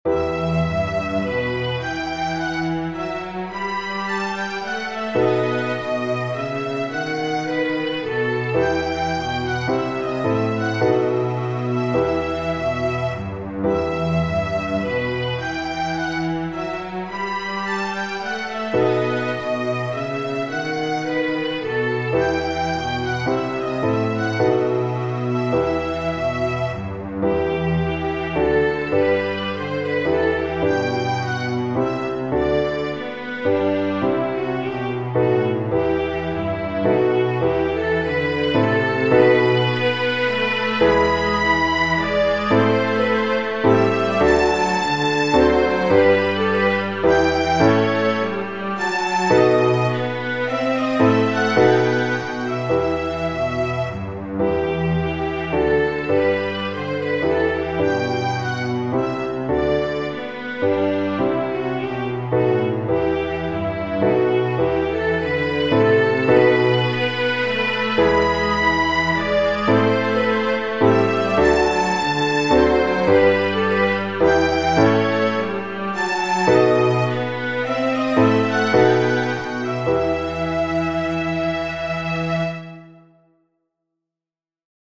Haendel, G. F. Genere: Religiose "Joseph" (HWV 59) is an oratorio by George Frideric Handel completed in the summer of 1743 with the English libretto by the Reverend James Miller. The Menuet is included in the Overture. Menuet from Joseph Menuet from Joseph letto 9 volte